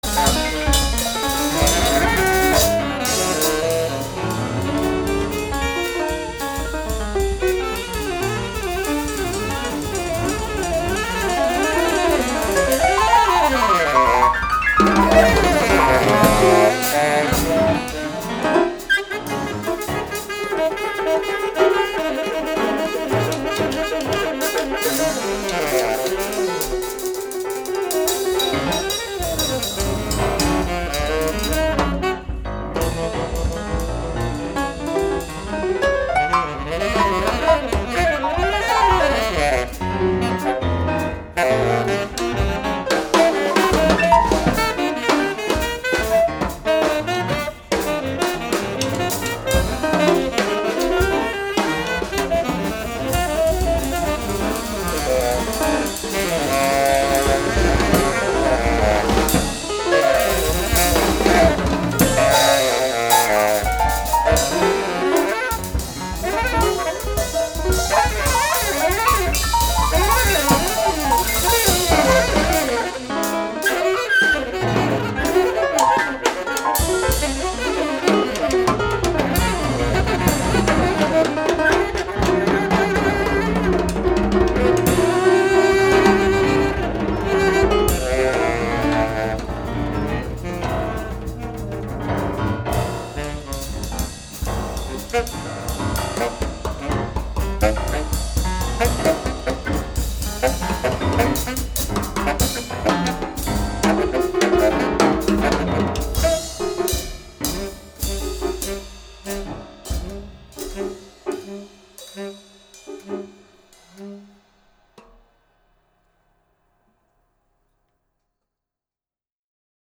American pianist
saxophonist & clarinetist